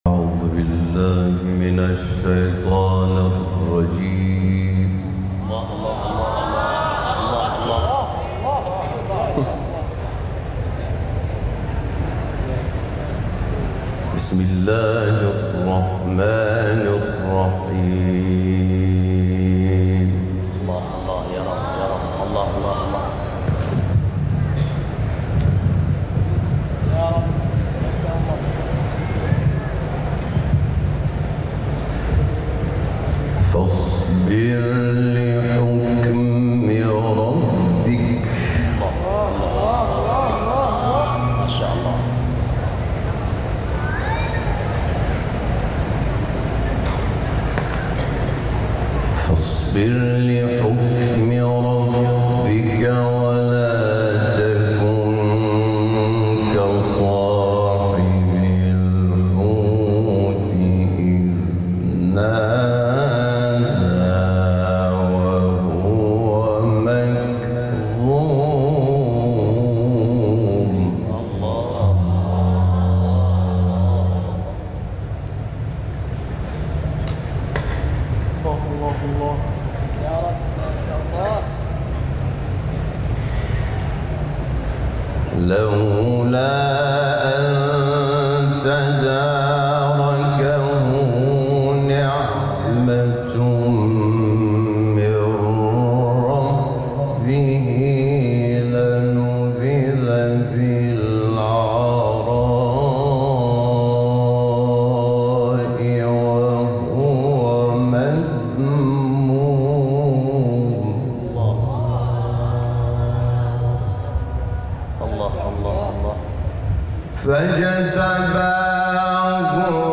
تلاوت سوره قلم و الحاقة در کشور اندونزی از استاد حامد شاکرنژاد
دانلود تلاوت زیبای آیه 48 تا 52 سوره مبارکه قلم و الحاقة با صدای دلنشین استاد حامد شاکرنژاد
در این بخش از ضیاءالصالحین، تلاوت زیبای سوره آیه 48 تا 52 سوره مبارکه قلم و آیه 1 تا 24 سوره مبارکه الحاقة را با صدای دلنشین استاد حامد شاکرنژاد به مدت 23 دقیقه با علاقه مندان به اشتراک می گذاریم.